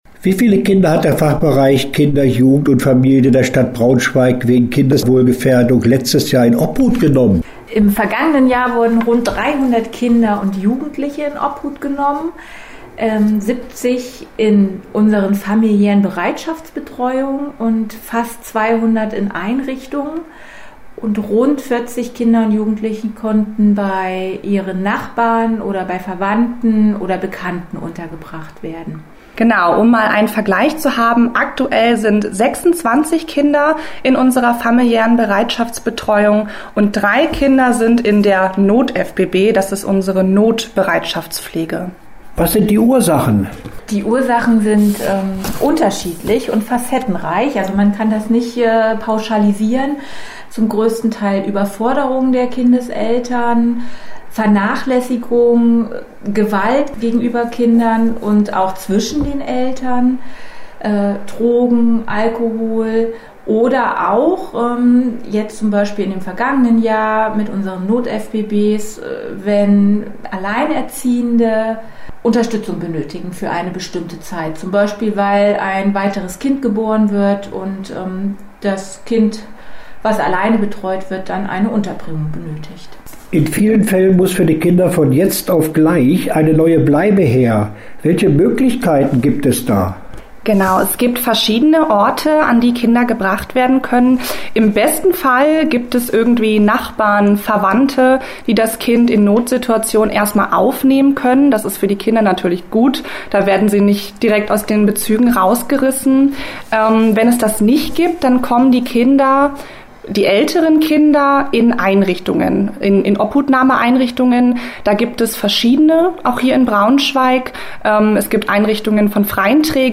Interview-Pflegefamilien_wa.mp3